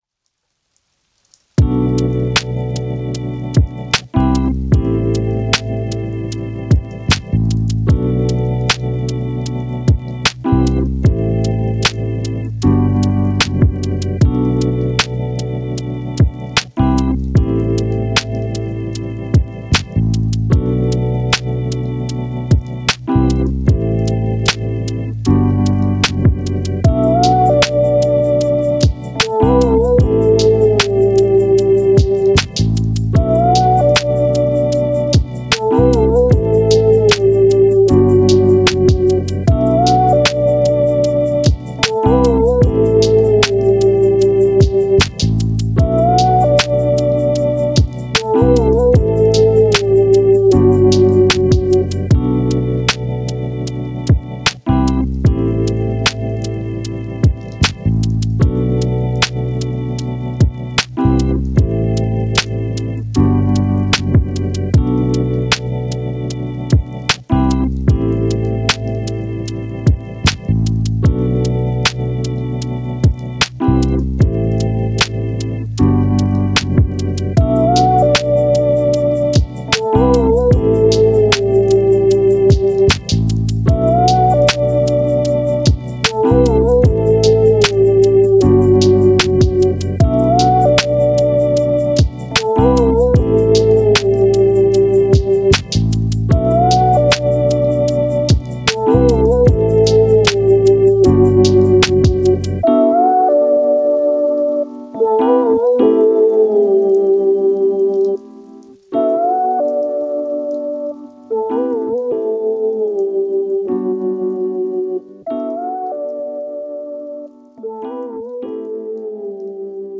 • musica chillout para reflexionar y relajarse
Música chillout para reflexionar y relajarse